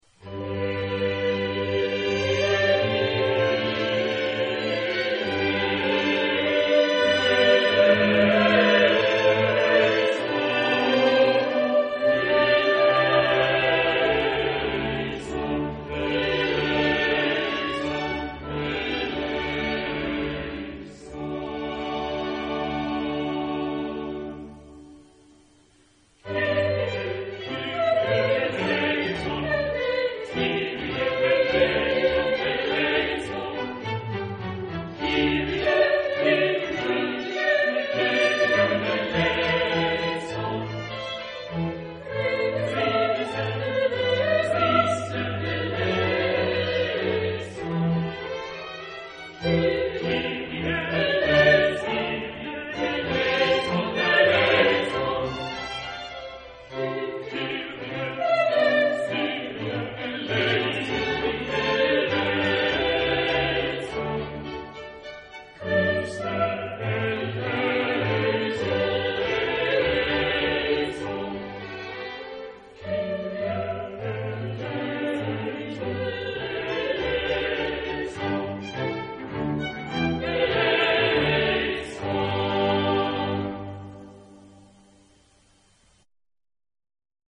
Genre-Style-Form: Sacred ; Mass
Type of Choir: SATB  (4 mixed voices )
Instruments: Organ (1)
Tonality: G major